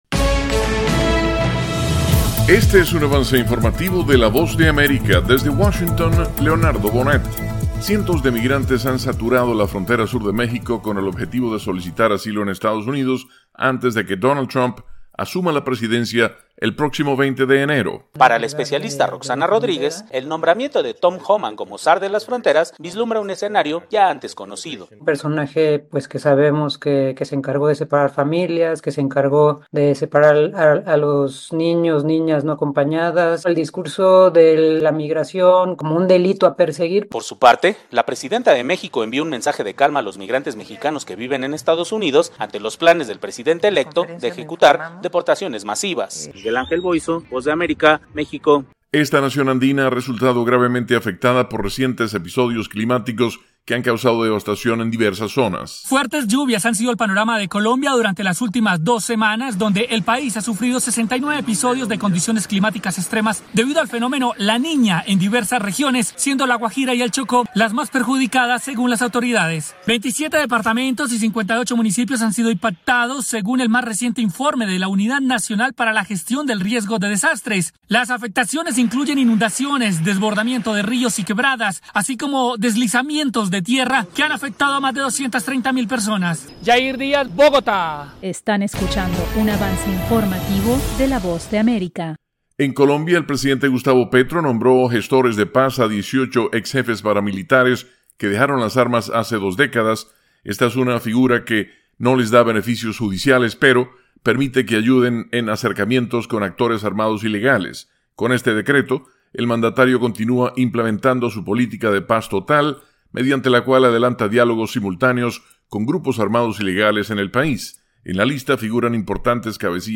El siguiente es un avance informativo presentado por la Voz de América